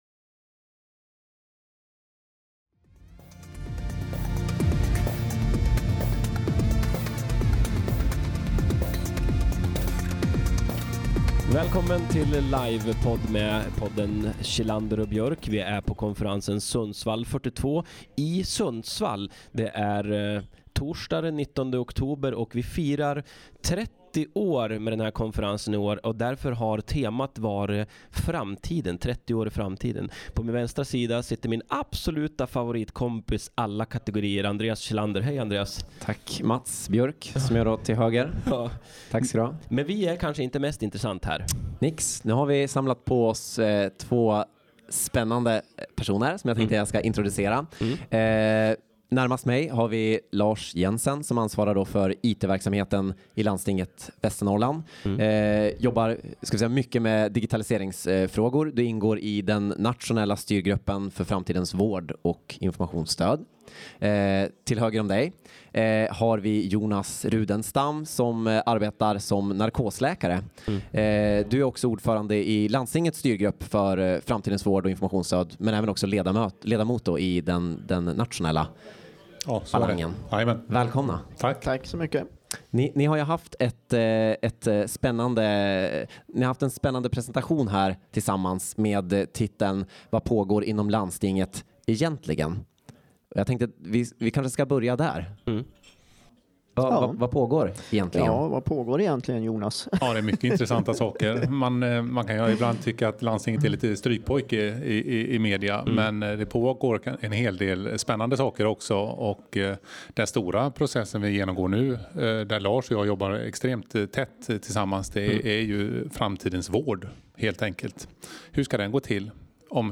Onsdag den 18/10 och Torsdag 19/10 livepoddar vi på konferensen Sundsvall 42. Vi kommer då att intervjua föreläsare och publik samt reflektera över konferensens innehåll löpande på plats.